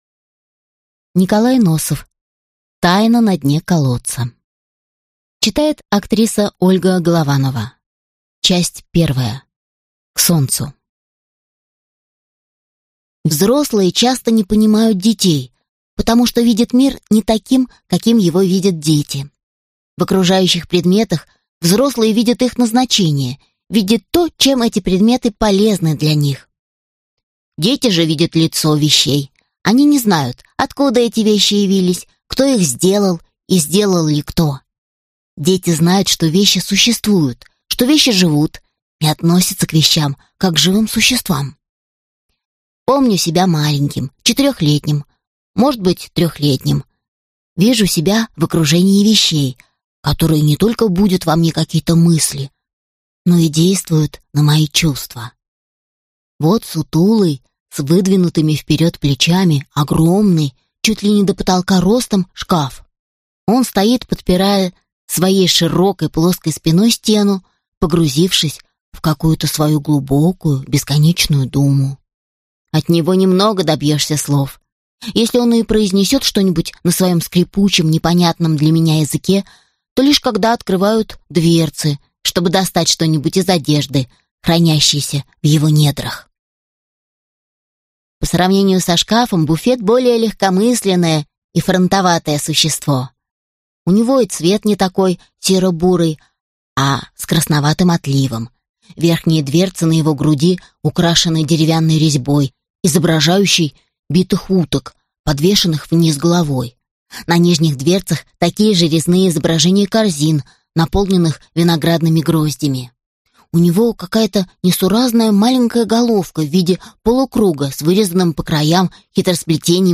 Аудиокнига Тайна на дне колодца | Библиотека аудиокниг